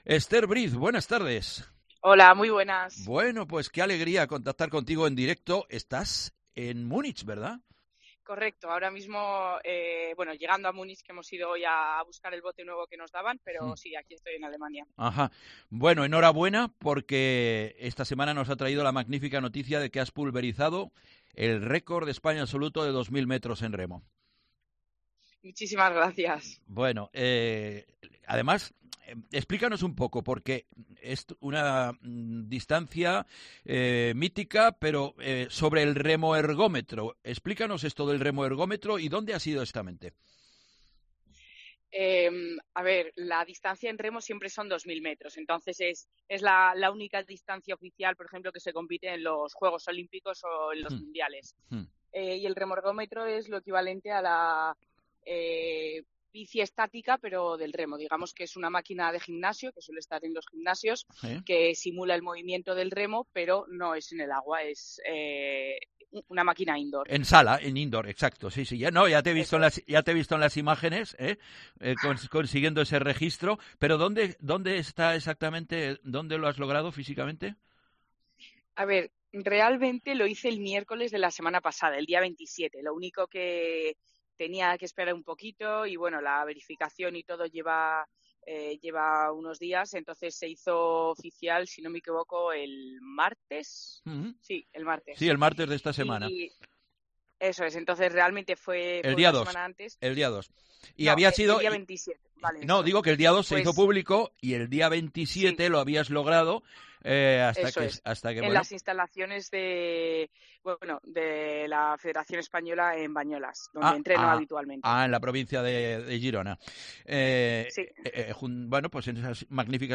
Entrevista a la remera zaragozana Esther Briz tras su récord de España absoluto de 2.000 metros.
Precisamente nos atendía Esther en directo este viernes desde Munich (Alemania), donde acaba de recoger la nueva embarcación con la que participará en los Juegos Olímpicos este verano.